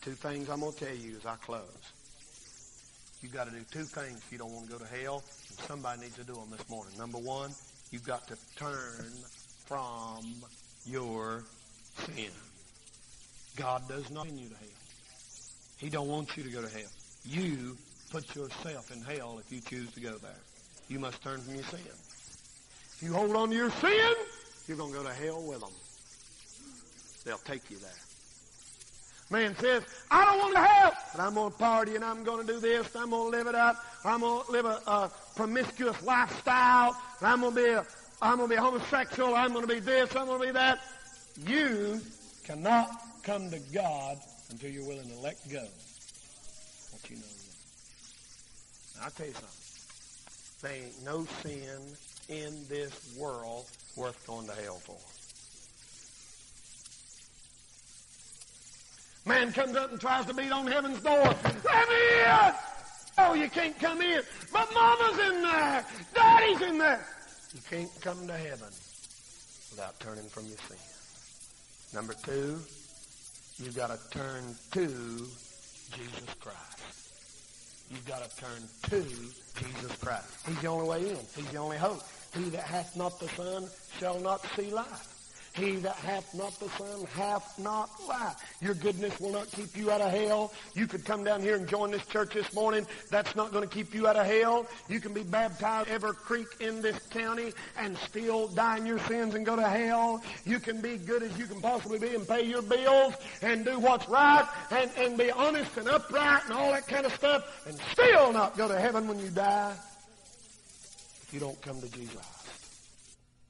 (1980s) (RARE SERMON)